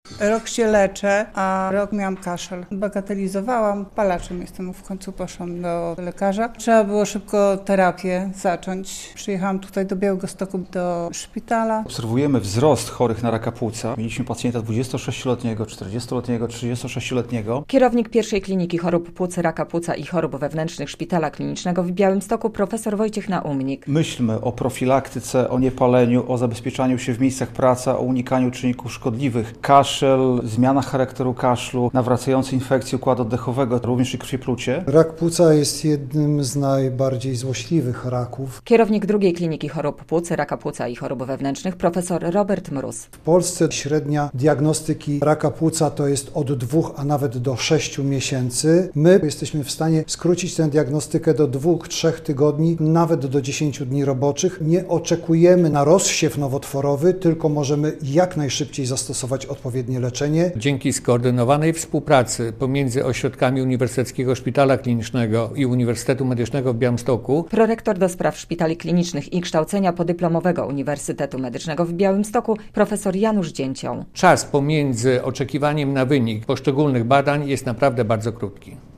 Radio Białystok | Wiadomości | Wiadomości - Każdego roku w Polsce przybywa chorych na raka płuca.